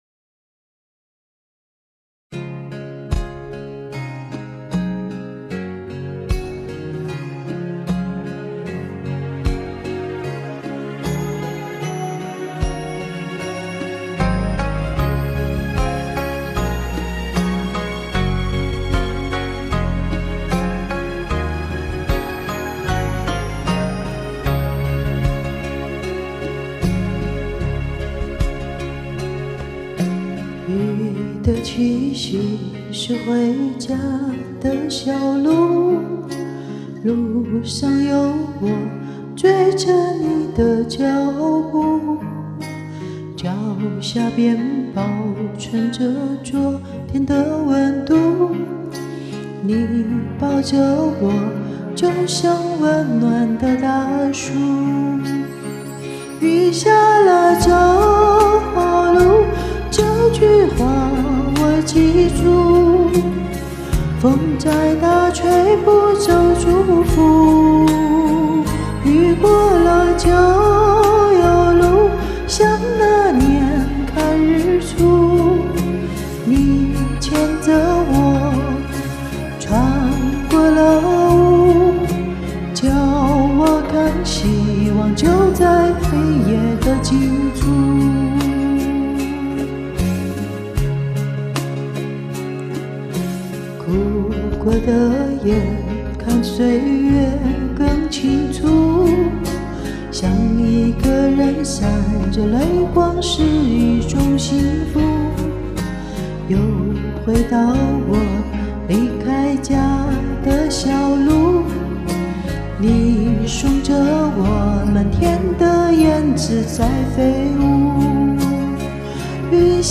你的歌，总是这么温柔，就好象羽毛轻轻拂过
听着无比的轻松。
哈哈，大概是底气不太足。